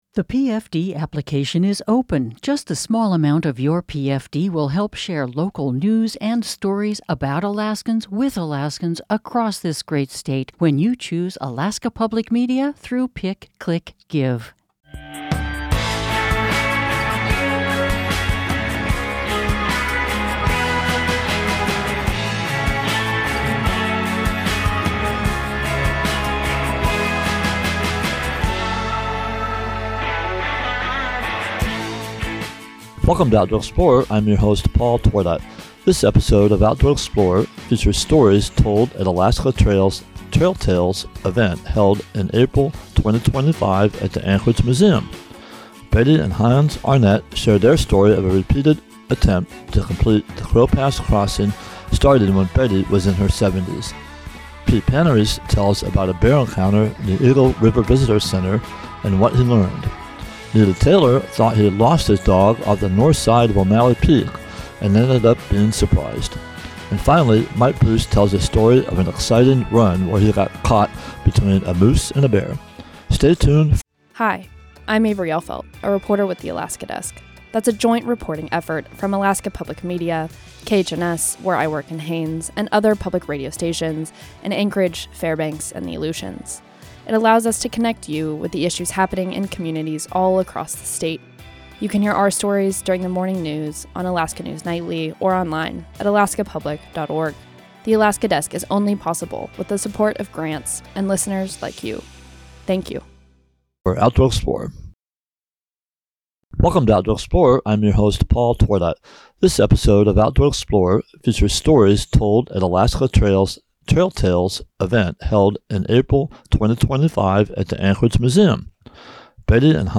This episode of Outdoor Explorer features these stories and more from Alaska Trails' "Trail Tales" event held in April, 2025 at the Anchorage Museum.